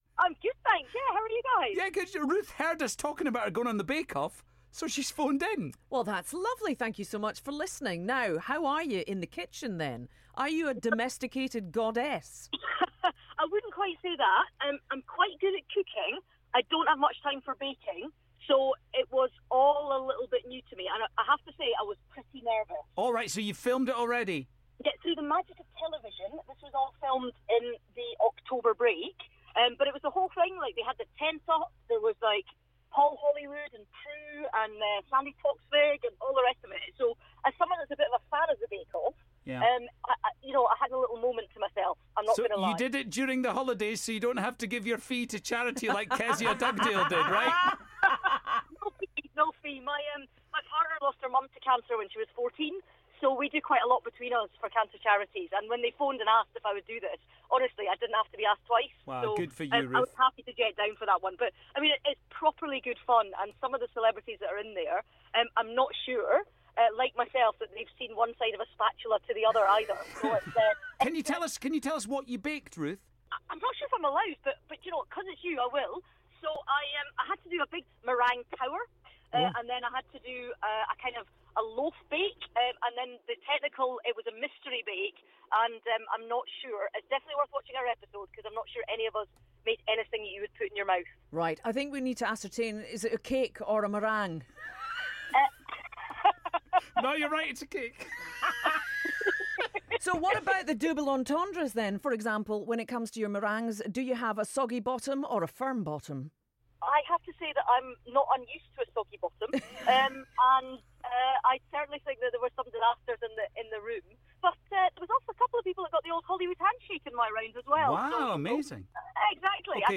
The Scottish Tory leader called Forth One's Boogie in the Morning to talk about her appearance on the Great British Bake Off and revealed her reasons for going on a celebrity version of the show